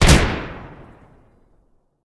rifle3.ogg